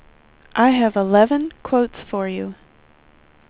WindowsXP / enduser / speech / tts / prompts / voices / sw / pcm8k / stock_12.wav